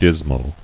[dis·mal]